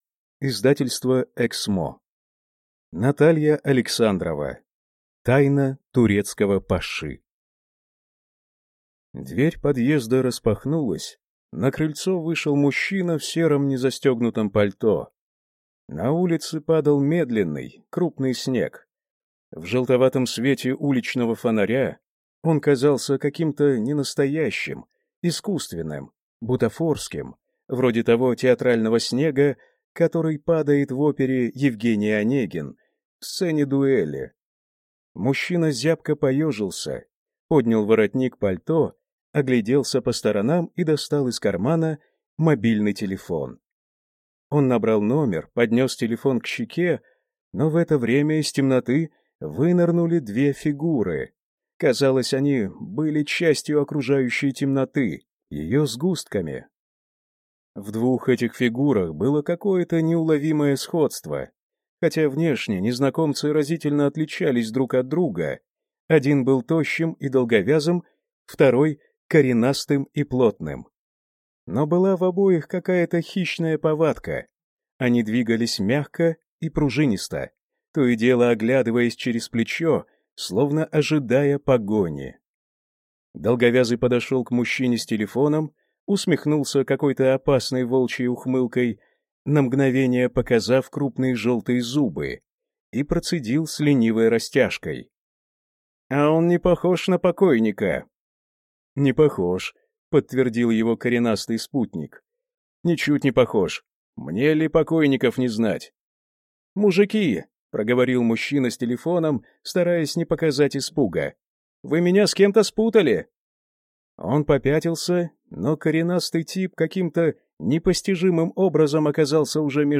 Тайна турецкого паши (слушать аудиокнигу бесплатно) - автор Наталья Александрова